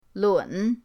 lun3.mp3